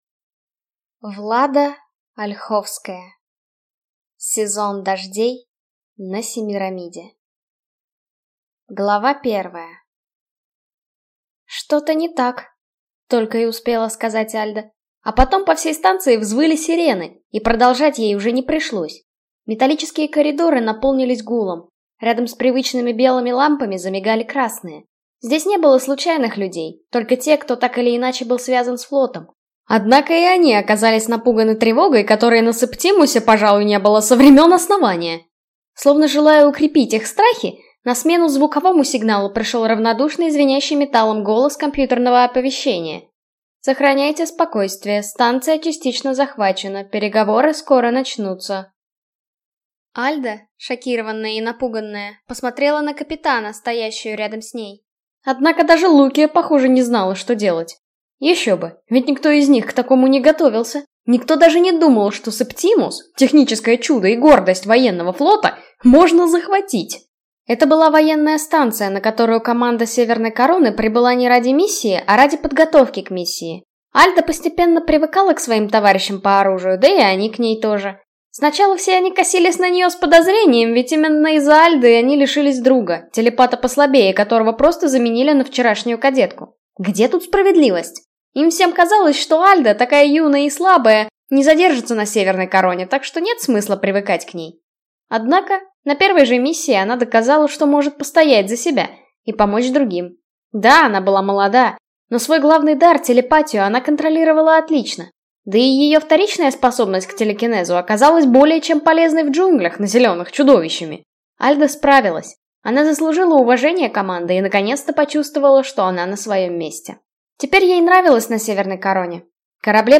Аудиокнига Сезон дождей на Семирамиде | Библиотека аудиокниг
Прослушать и бесплатно скачать фрагмент аудиокниги